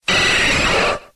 Cri de Nosferalto dans Pokémon X et Y.